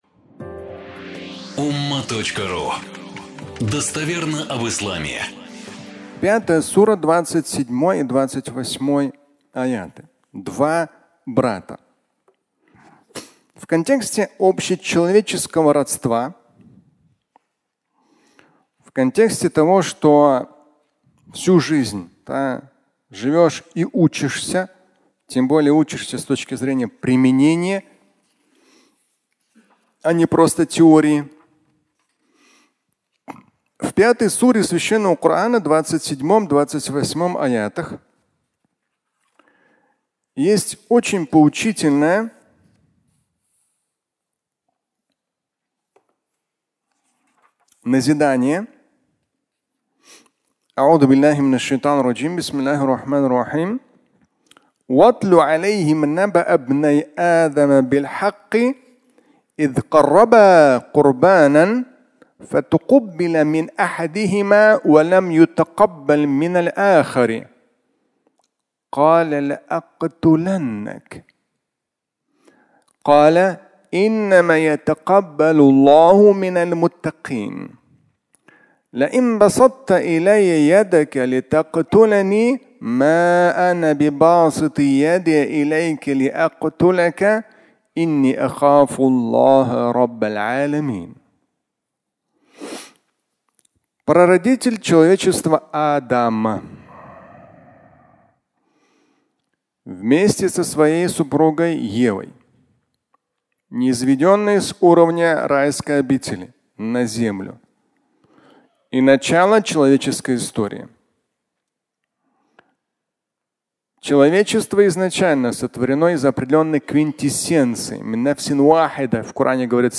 Два брата (аудиолекция)